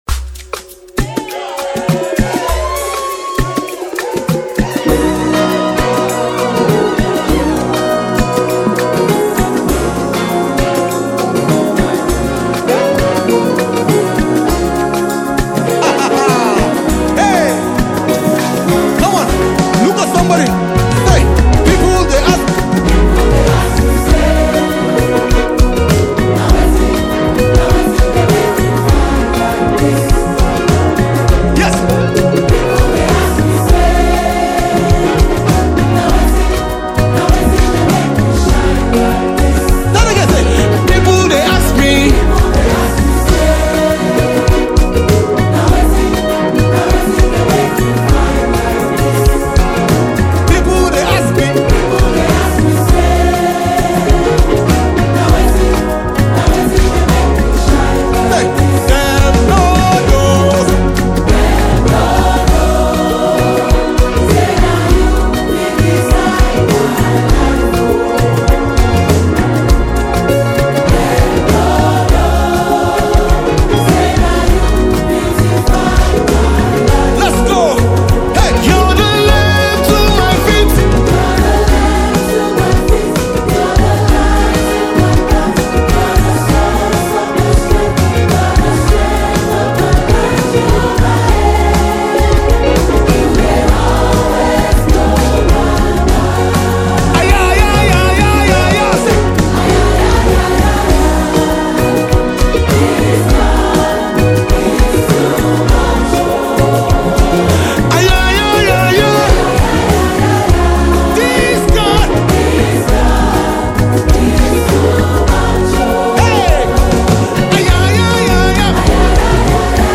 gospel
a powerful choral presence